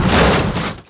GroundCrash_00.mp3